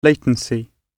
Transcription and pronunciation of the word "latency" in British and American variants.